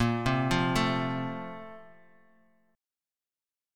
BbM7sus2 chord